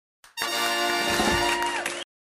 На этой странице собраны энергичные звуки победы и выигрыша — от фанфар до коротких мелодичных оповещений.
Толпа людей и выигрыш